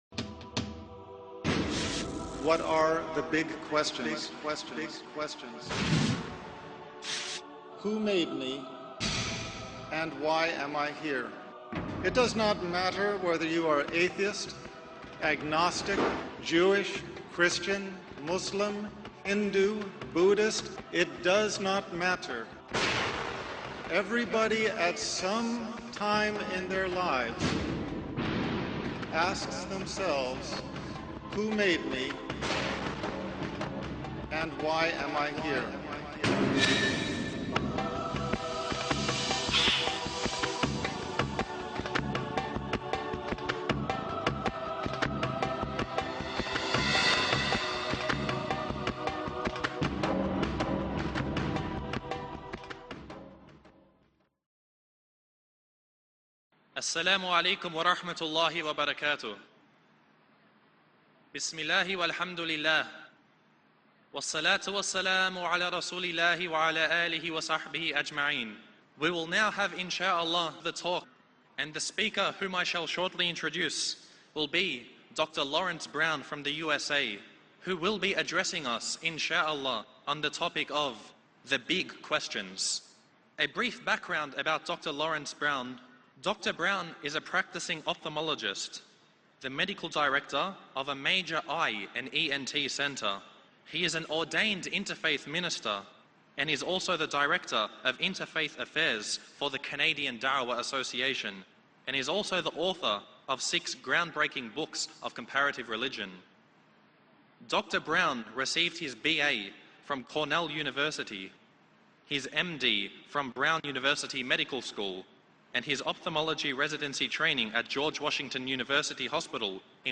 delivered to a live audience
His lecture is a masterclass in rational faith: it does not ask us to abandon reason to find God, but to follow reason to its honest conclusion — and let that conclusion lead us to Islam.